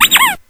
squeek.wav